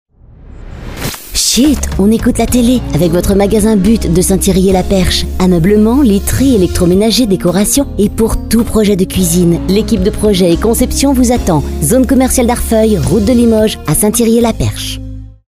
et voici le spot de votre But St Yrieix la perche